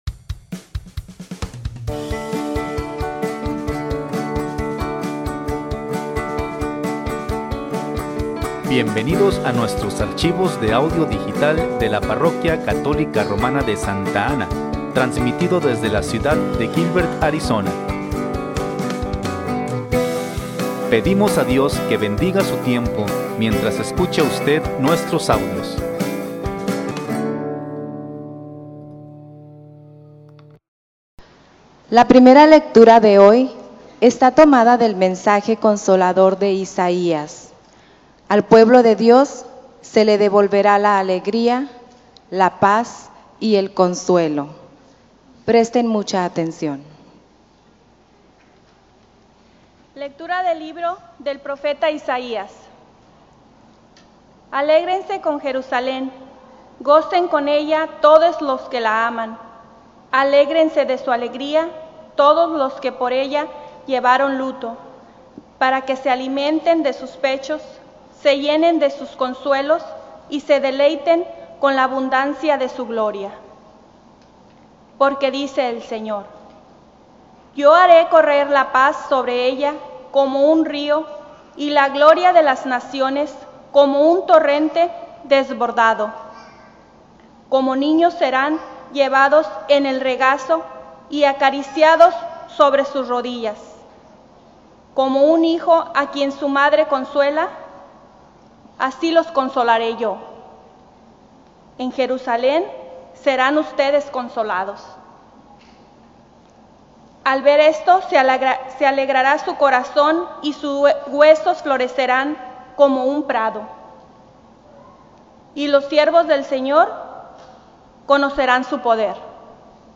XIV Domingo de Tiempo Ordinario (Lecturas) | St. Anne